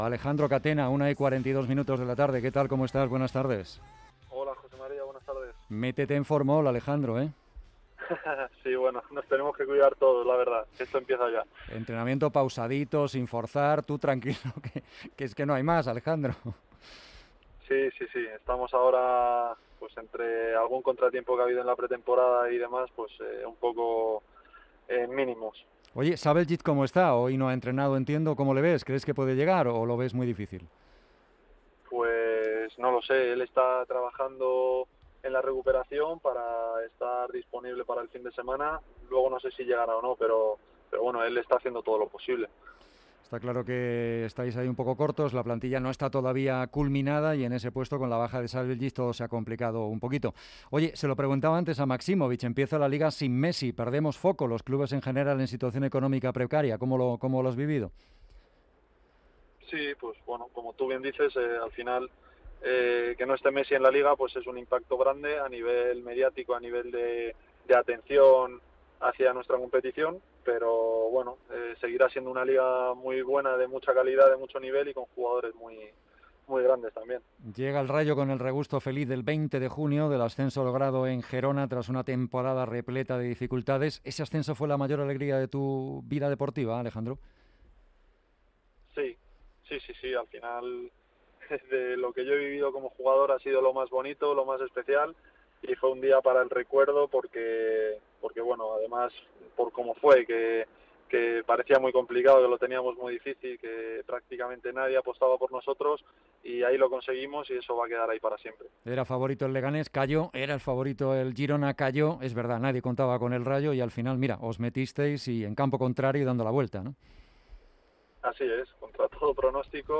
Entrevista con el jugador del Rayo Vallecano, Alejandro Catena.